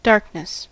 darkness: Wikimedia Commons US English Pronunciations
En-us-darkness.WAV